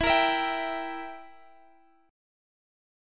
Listen to Edim strummed